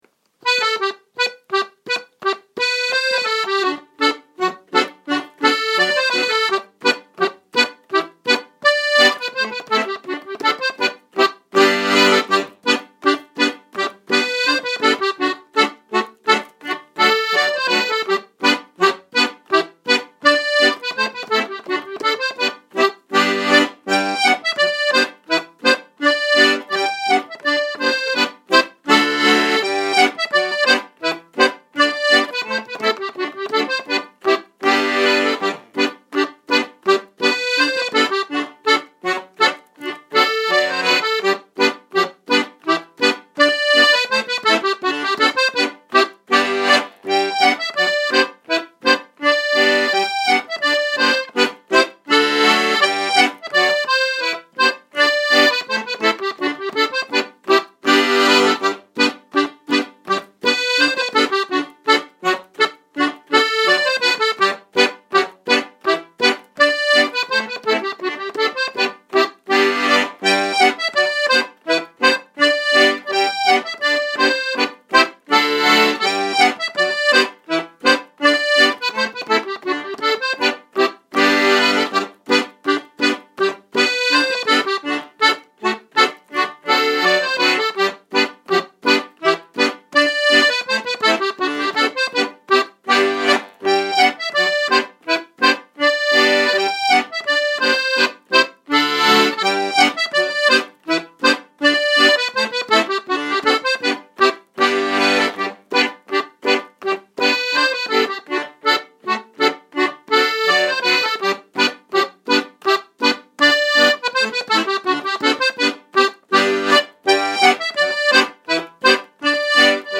Clog Ossy Jig Rakes of Mallow